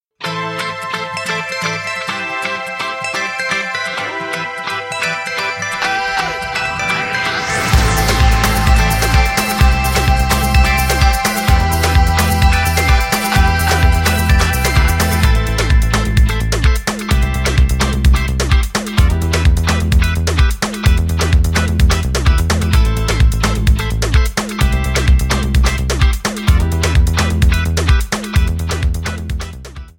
Backing track files: 2000s (3150)